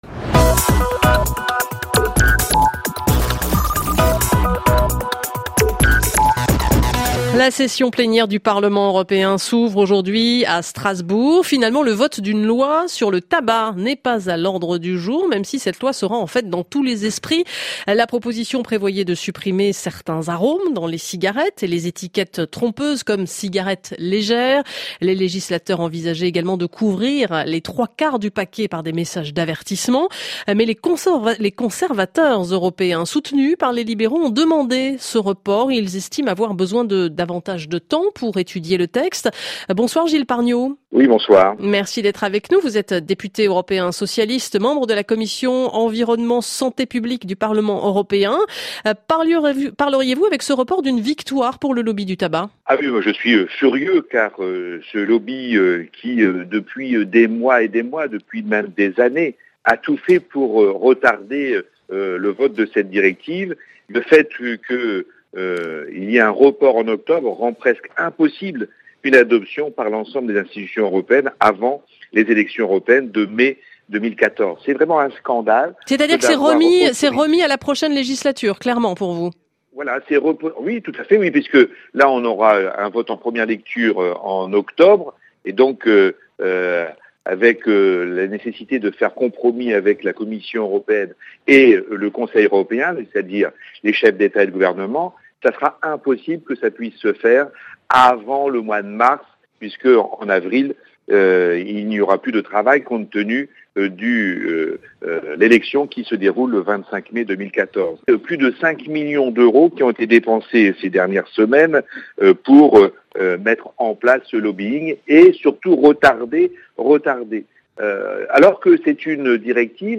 Interview RFI sur le report de la directive Tabac
RfiHier, je suis revenu sur l'impact du report du vote de la directive Tabac dans l'émission "Allô Bruxelles" diffusée sur RFI.